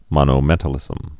(mŏnō-mĕtl-ĭzəm)